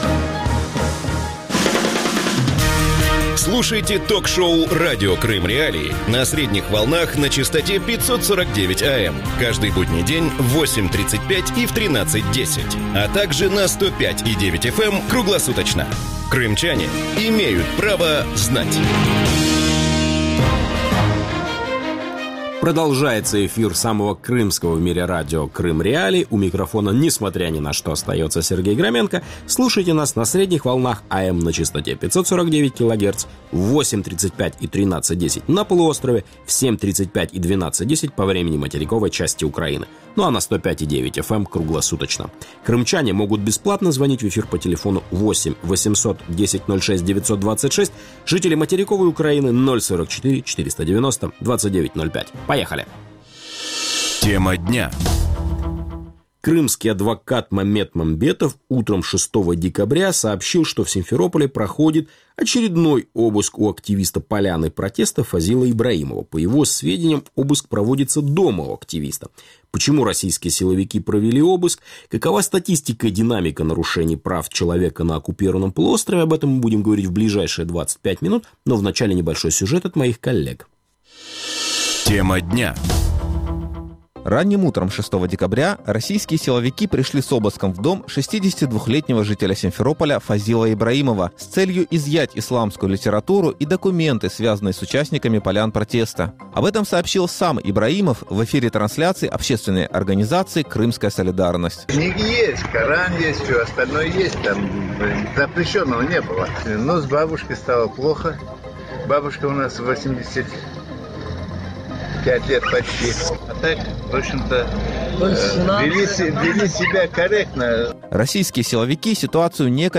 Радио Крым.Реалии эфире 24 часа в сутки, 7 дней в неделю.